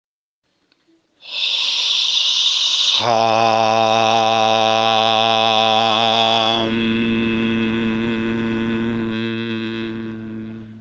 • Dopo aver preso confidenza con il punto si potrà usare il mantra HAM/HEM con tonalità lenta bassa e grave. Ripetere 7 volte.